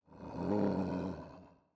Babushka / audio / sfx / Animals / SFX_Wolf_Snarl_03.wav
SFX_Wolf_Snarl_03.wav